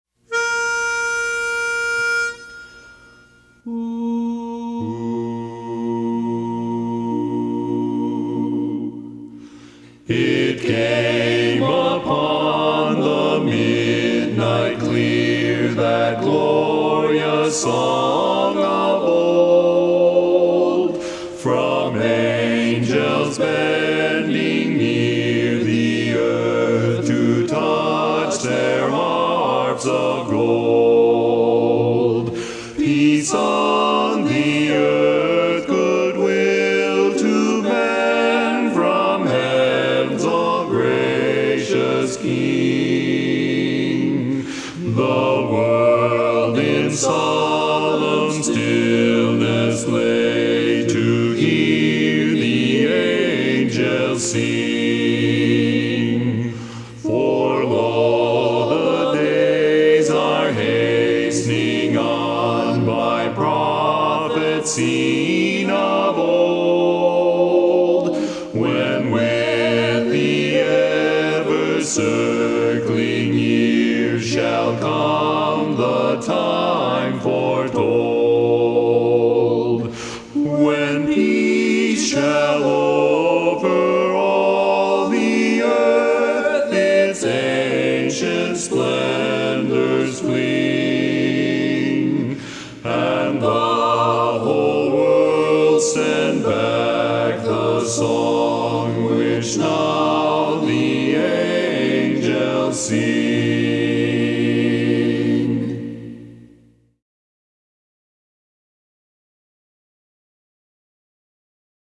Barbershop
Bari